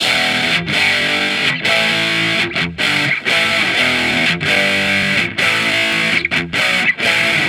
Guitar Licks 130BPM (4).wav